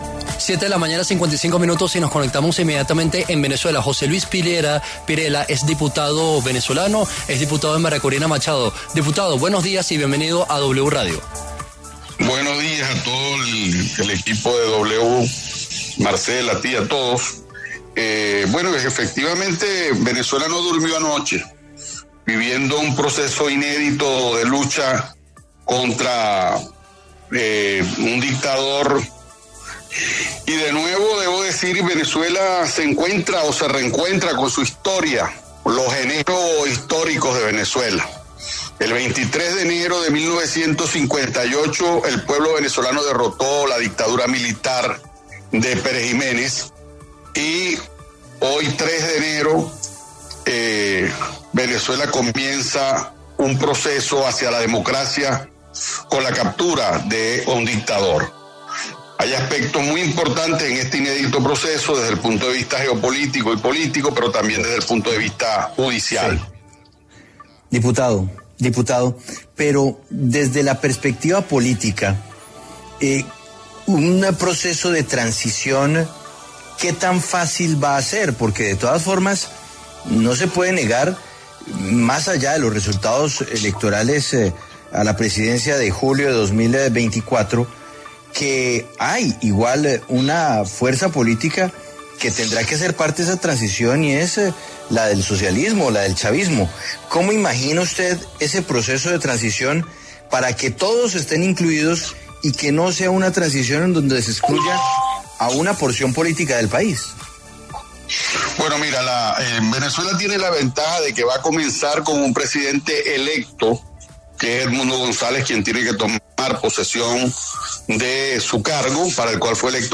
La W conversó con José Luis Pirela, diputado venezolano, quien se refirió a la acción militar de Estados Unidos y la captura de Nicolás Maduro, lo que deja en vilo el futuro político y social en ese país.